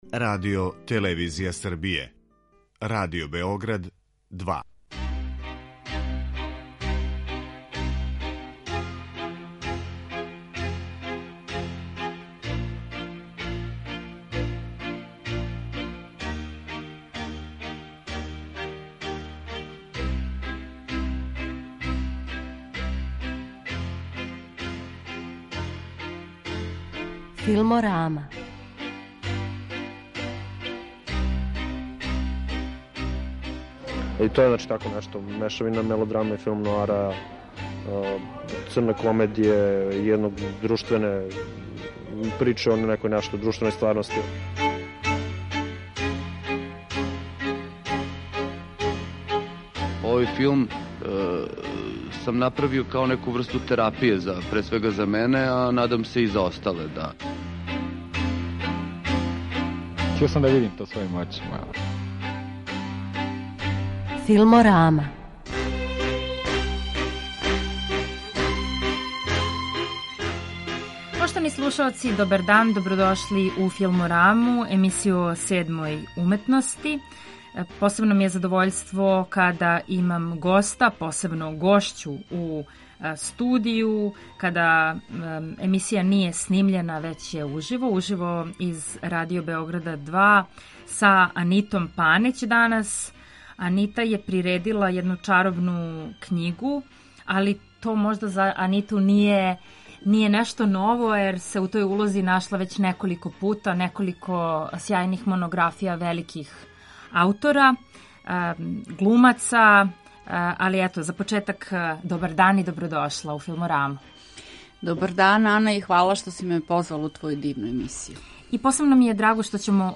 О свему овоме данас у разговору